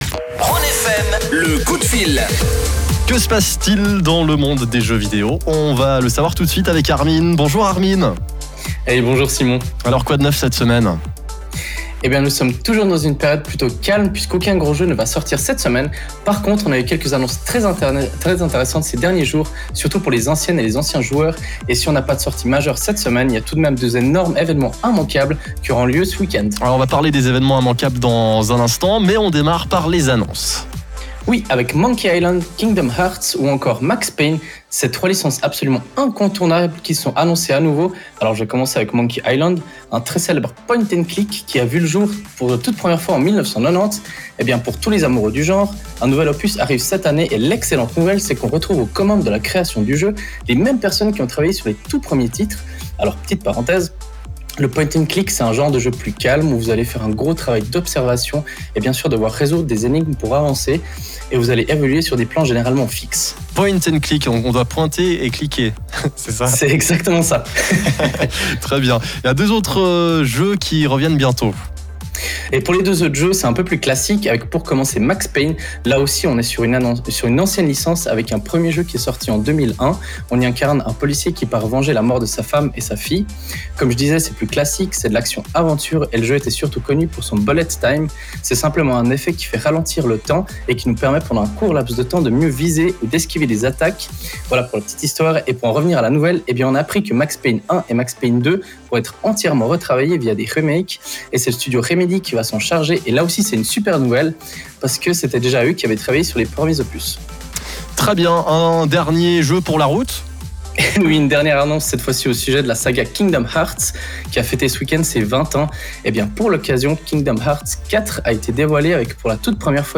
Après une semaine de repos, on reprend notre chronique sur la radio Rhône FM. Pour l’occasion, on y traite de la grosse sortie de la semaine, à savoir Nintendo Switch Sports. De F1 2022, fraichement annoncé, ainsi que de God of War Ragnarök. On termine avec un peu de matériel, mais aussi un événement à ne pas manquer ce weekend.